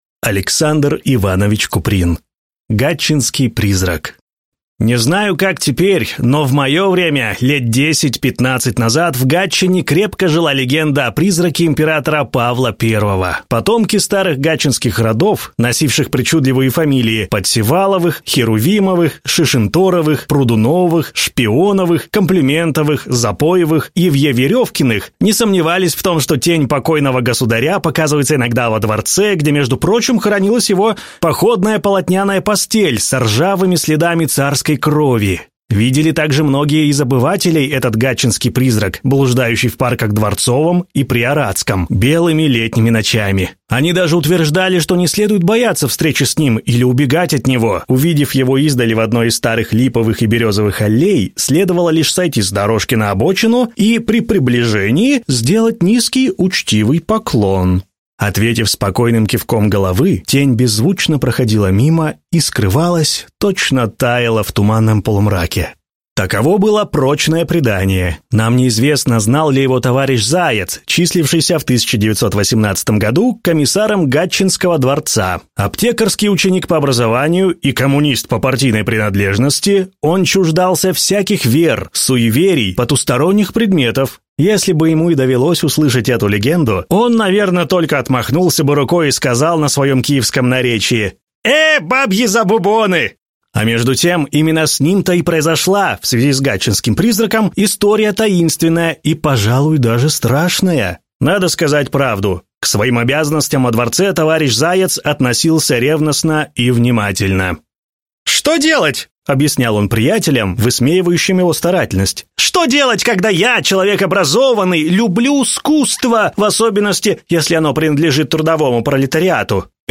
Аудиокнига Гатчинский призрак | Библиотека аудиокниг